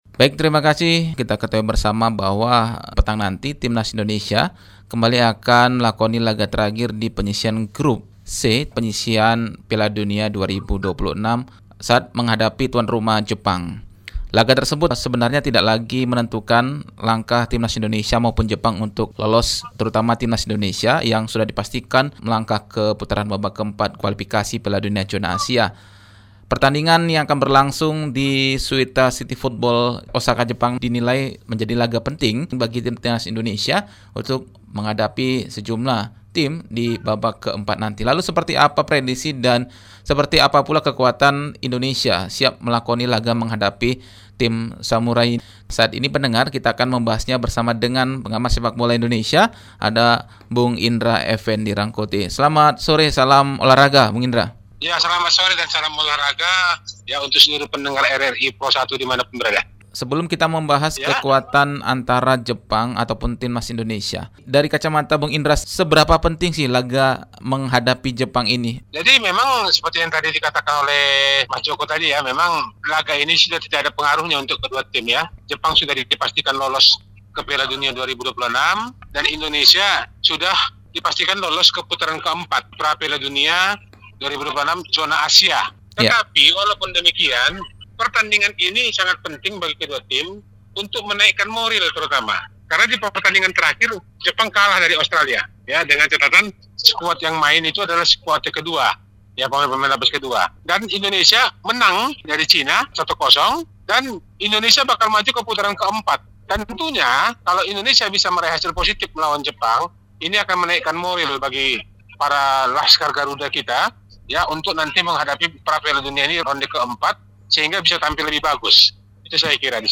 RRI MEDAN - WAWANCARA JELANG INDONESIA HADAPI JEPANG | PPID LPP RRI